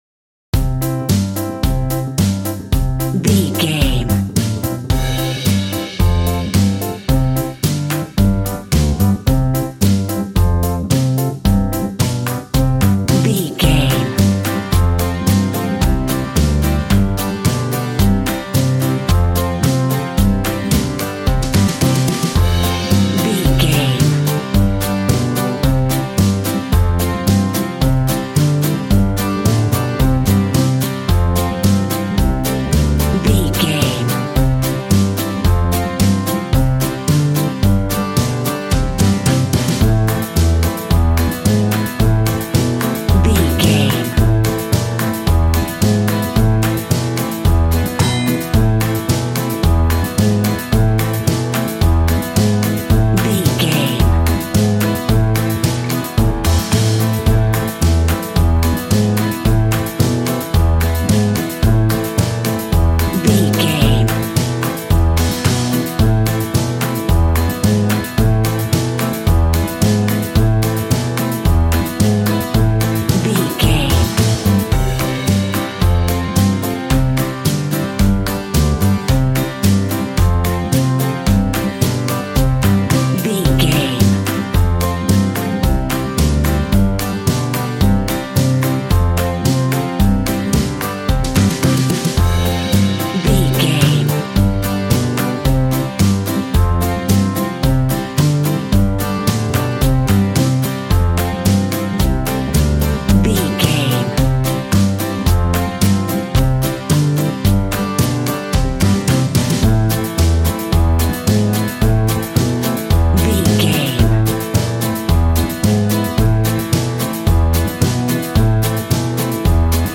Upbeat, uptempo and exciting!
Ionian/Major
bouncy
electric piano
electric guitar
drum machine